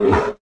Sound / sound / monster / bear / damage_2.wav
damage_2.wav